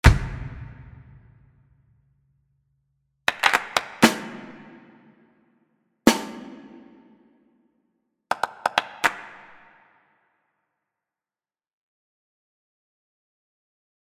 Um die Dichte, Transparenz und Auflösung der Raumsimulationen zu beurteilen, habe ich kurze Impulse, nämlich eine Bassdrum, eine Snare und Claps verwendet.
Auch die Bässe werden mitgenommen.